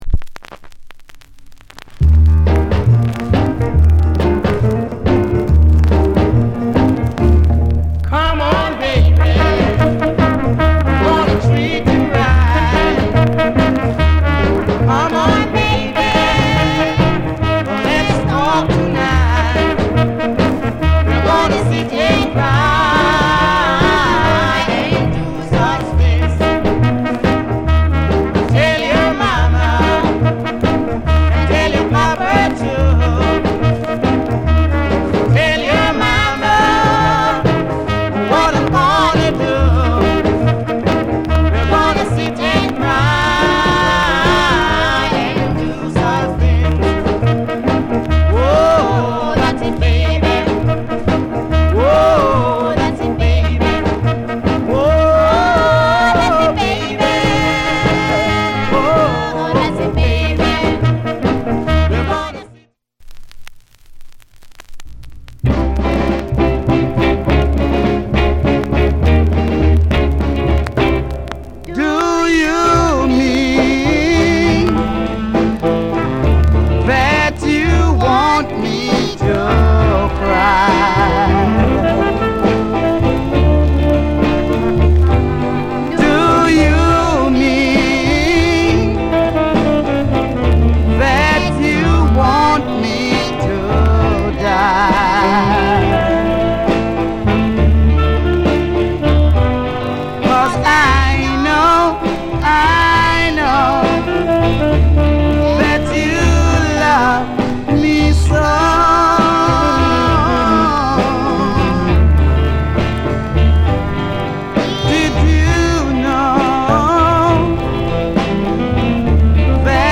* Jamaican Rock'n'Roll/Ballad